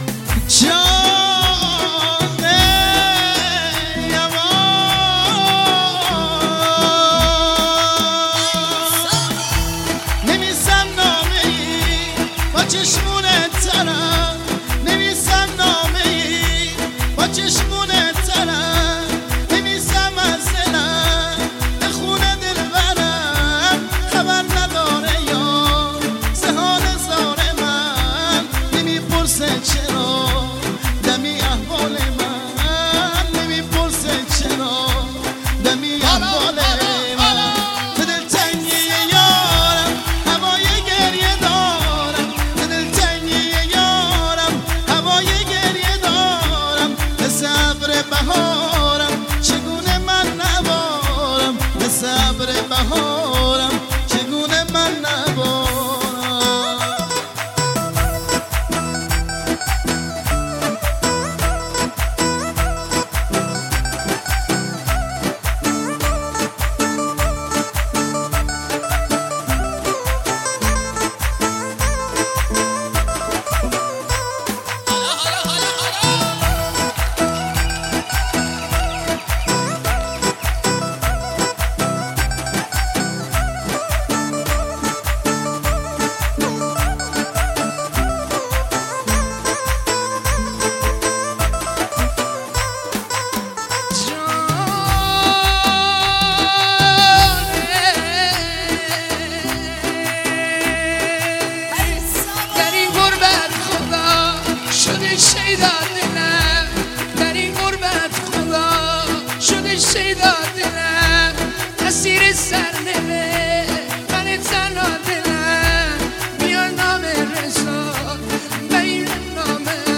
نسخه دو نفره